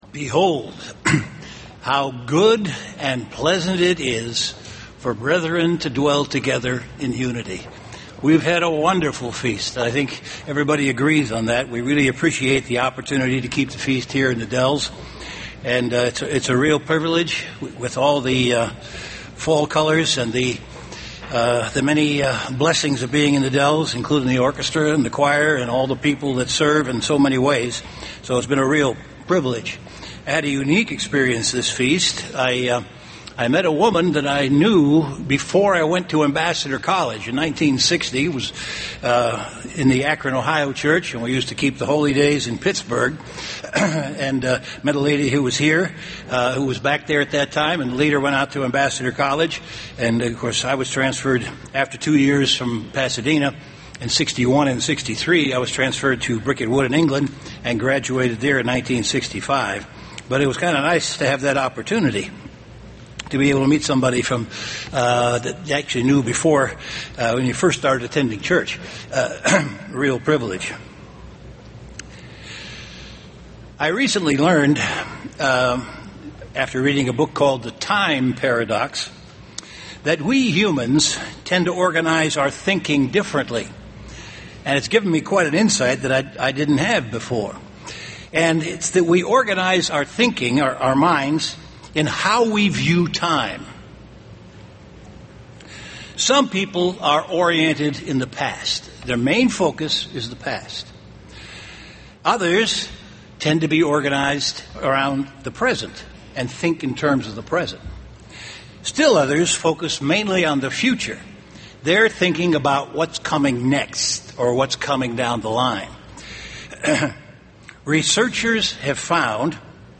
This sermon was given at the Wisconsin Dells, Wisconsin 2011 Feast site.